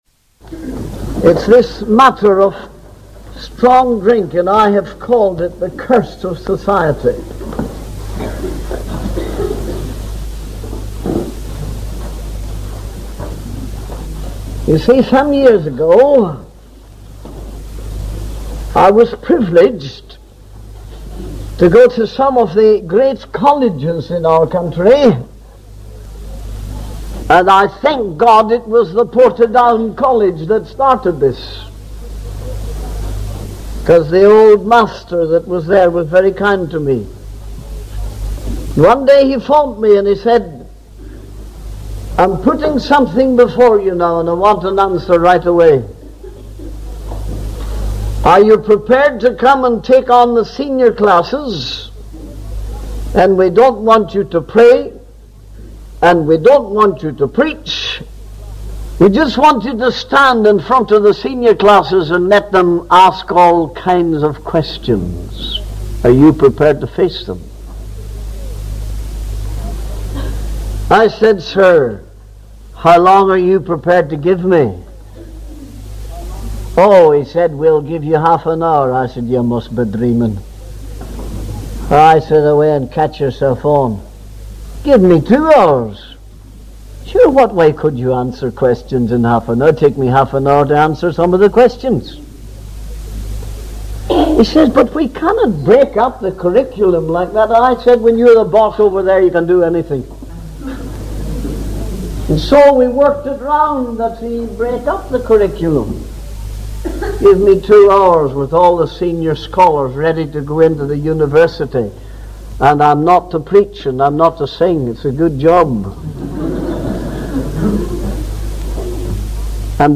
In this sermon, the preacher discusses the negative effects of the book, highlighting its role in bringing misery, immorality, blasphemy, stupidity, incapability, and leading to eternity. The preacher also mentions the upcoming topic of divorce and invites the audience to bring their Bibles for a deeper understanding. The sermon includes singing of hymns, specifically referencing hymn number 848, which emphasizes the grand theme of God's ability to deliver.